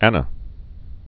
(ănə)